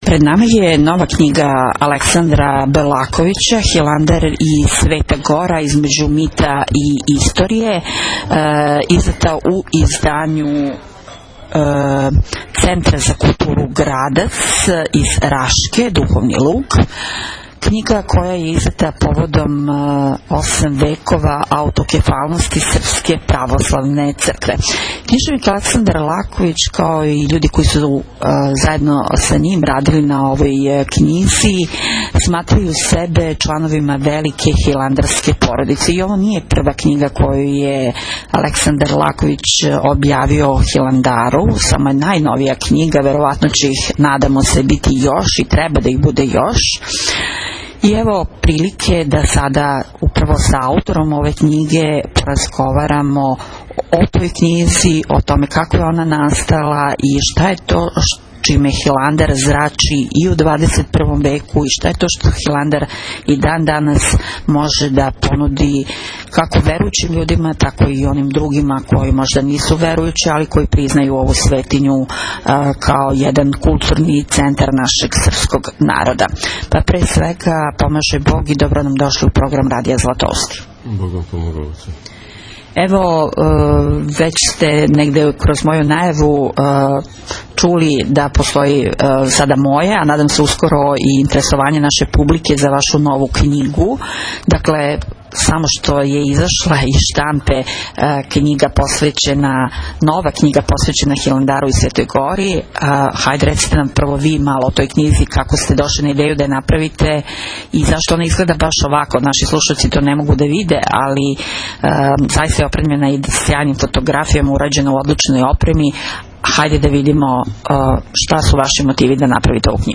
ГОСТОПРИМНИЦА, разговор